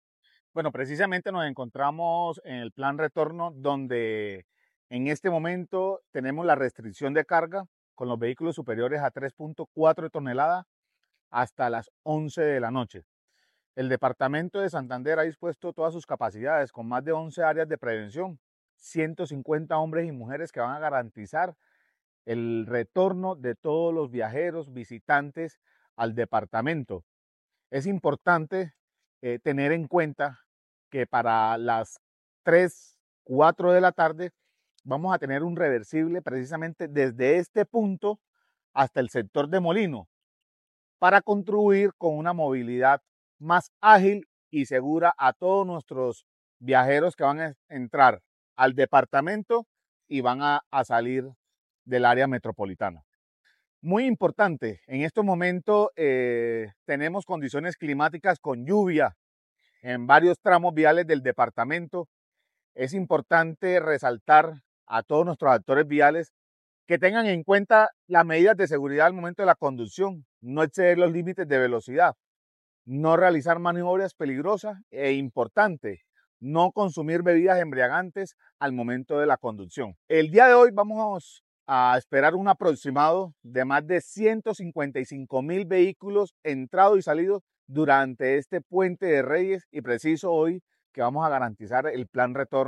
Comandante del Departamento de Policía Santander, el coronel Néstor Arévalo Montenegro